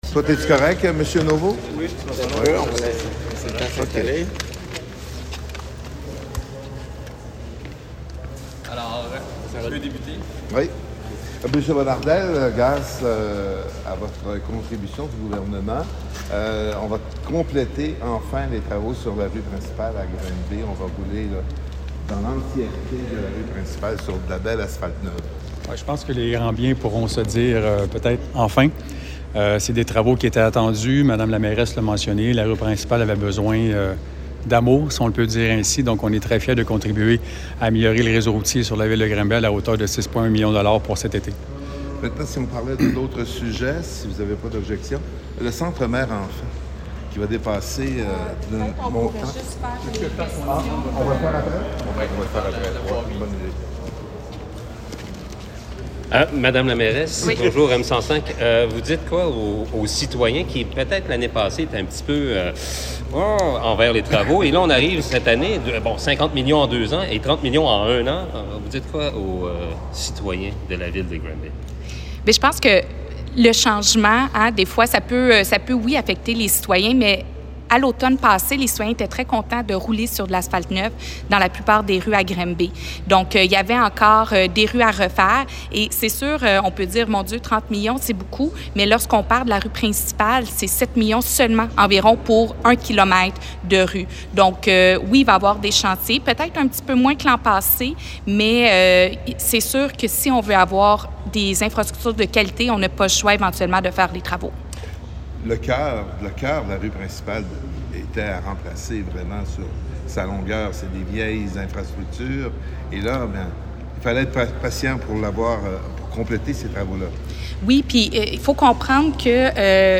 Le député de Granby, François Bonnardel :